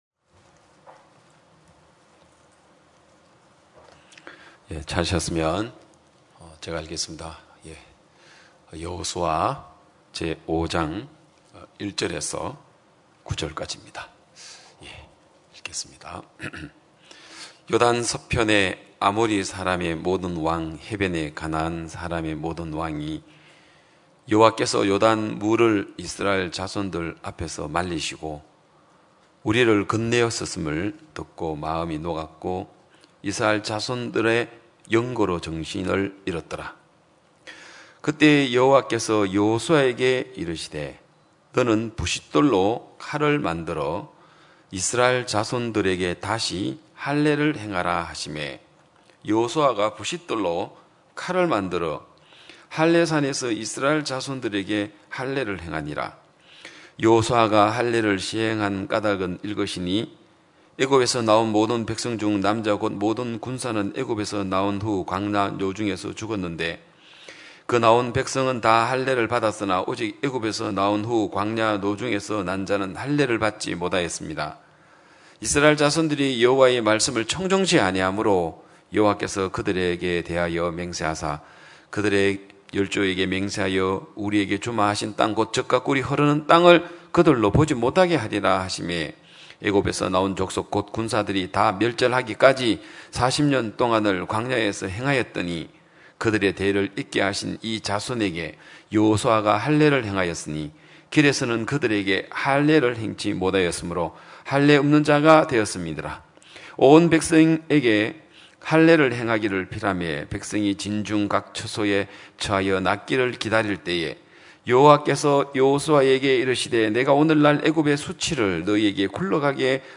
2022년 6월 12일 기쁜소식양천교회 주일오전예배
성도들이 모두 교회에 모여 말씀을 듣는 주일 예배의 설교는, 한 주간 우리 마음을 채웠던 생각을 내려두고 하나님의 말씀으로 가득 채우는 시간입니다.